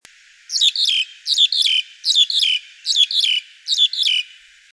2013鐵道路烏領椋鳥5.mp3
黑領椋鳥 Gracupica nigricollis
高雄市 鼓山區 鐵道
錄音環境 鐵道喬木
一群4-5隻
Sennheiser 型號 ME 67